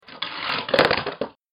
جلوه های صوتی
دانلود صدای ماشین 17 از ساعد نیوز با لینک مستقیم و کیفیت بالا